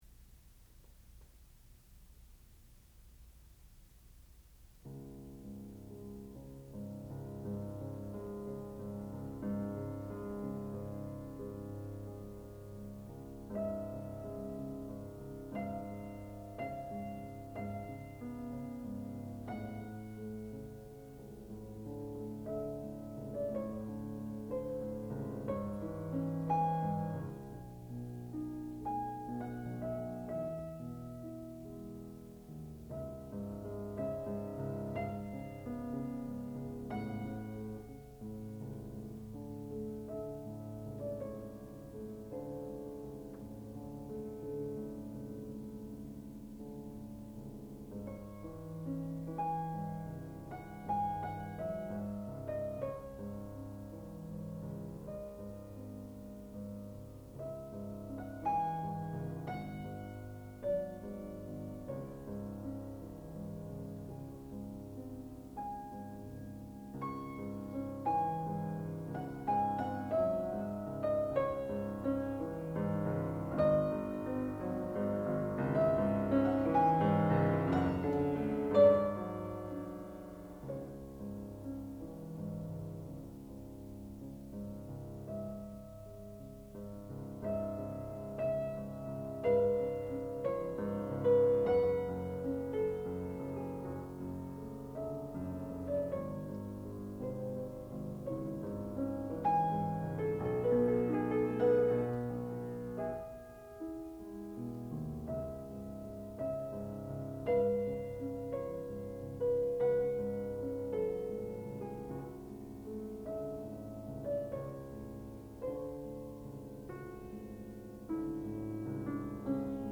sound recording-musical
classical music
piano
Student Recital